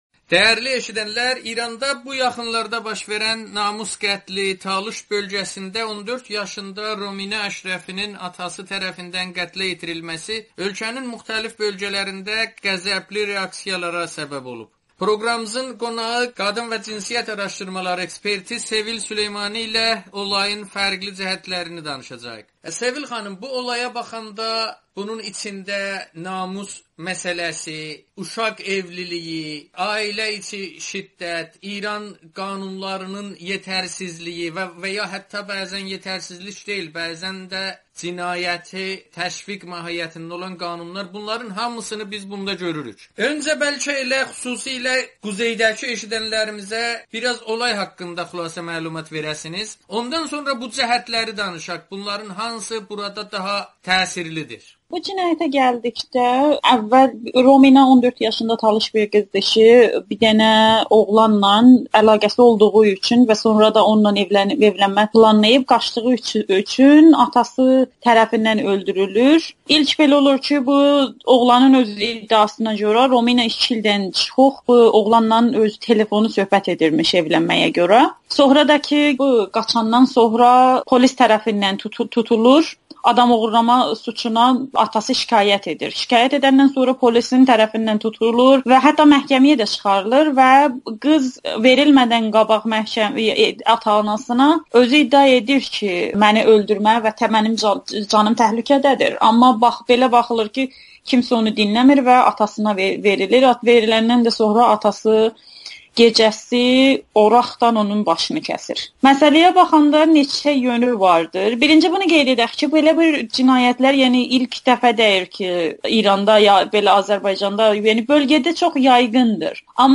söhbətdə